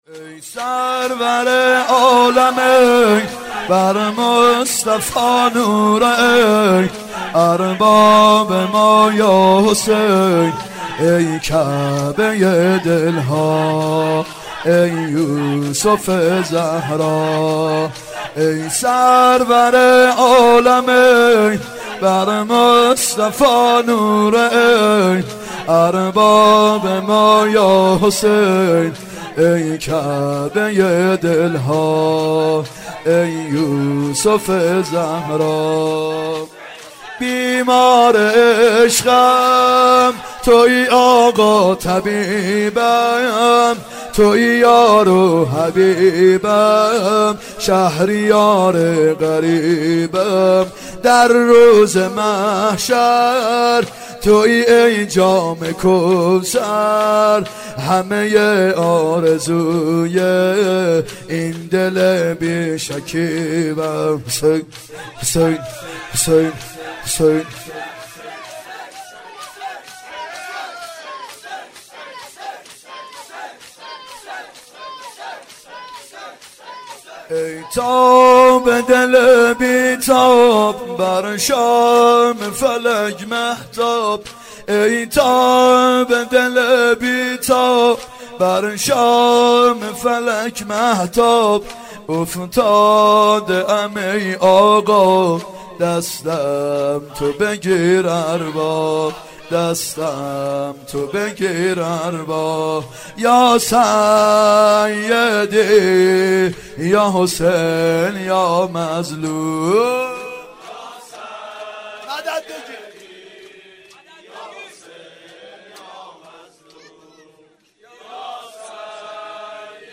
فاطمیه اول 91 شب اول شور (ای سرور عالمین بر مصطفی نور عین
فاطمیه اول هیئت یامهدی (عج)